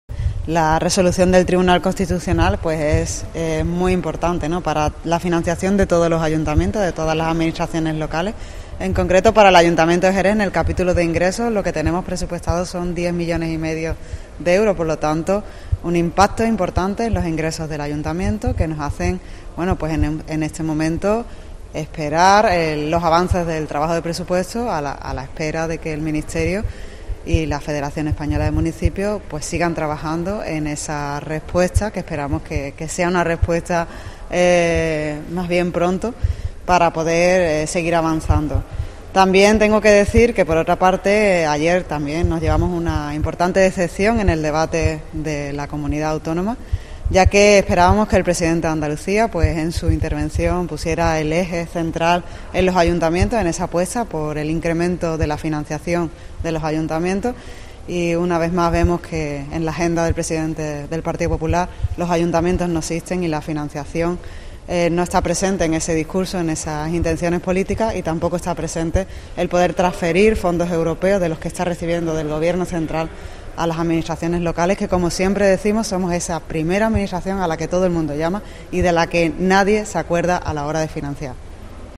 En declaraciones a los periodistas, Álvarez ha indicado que espera que el Ministerio de Hacienda y la Federación Española de Municipios y Provincias (FEMP), que han mostrado diligencia para la búsqueda de una solución, "sigan trabajando en una respuesta para poder seguir avanzando".
Escucha a Laura Álvarez, teniente de alcaldesa delegada de Economía del Ayuntamiento de Jerez